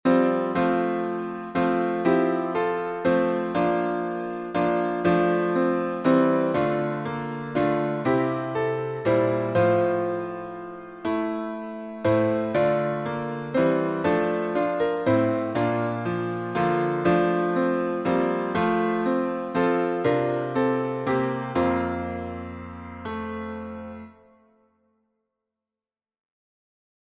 Words by F. B. P. 16th century Tune: American folk tune Key signature: F major (1 flat) Time signat